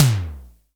TOM SIMM T1.wav